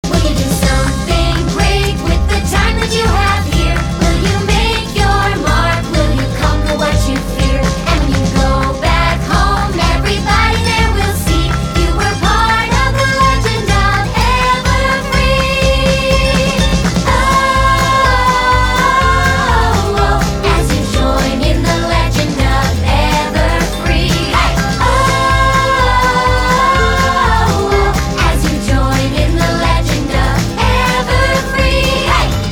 веселые
забавный голос
танцевальные
детские
Музыка из мультфильма